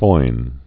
(foin) Archaic